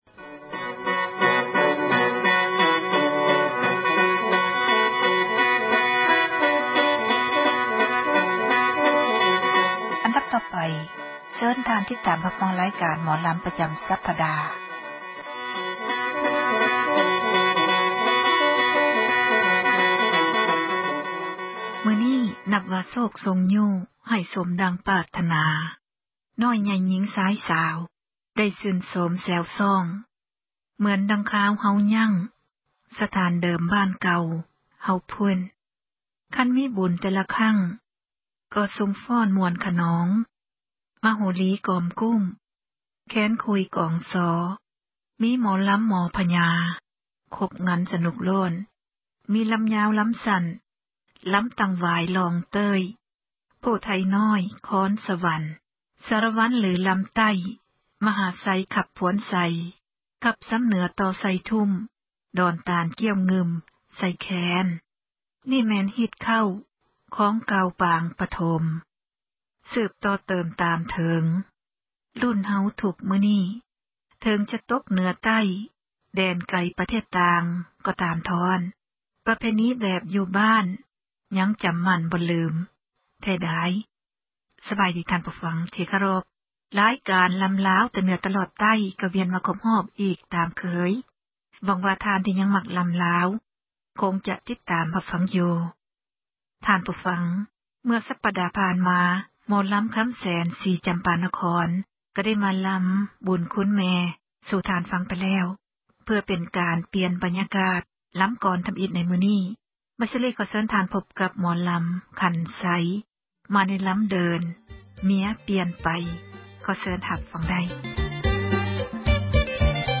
ຣາຍການ ໝໍລຳລາວ ປະຈຳ ສັປດາ ສເນີໂດຍ